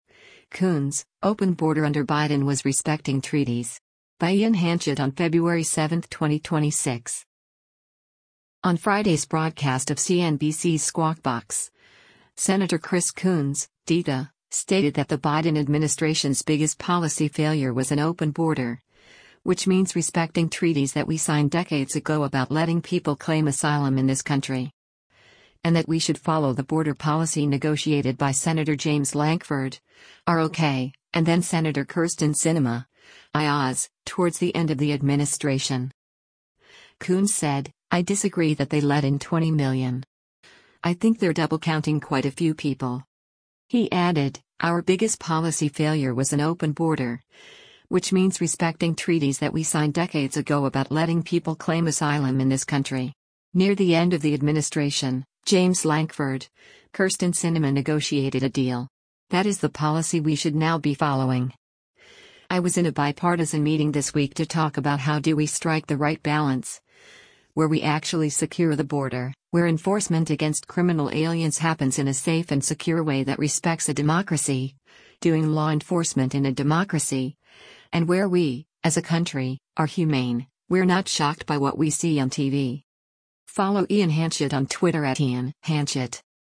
On Friday’s broadcast of CNBC’s “Squawk Box,” Sen. Chris Coons (D-DE) stated that the Biden administration’s “biggest policy failure was an ‘open border’, which means respecting treaties that we signed decades ago about letting people claim asylum in this country.” And that we should follow the border policy negotiated by Sen. James Lankford (R-OK) and then-Sen. Kyrsten Sinema (I-AZ) towards the end of the administration.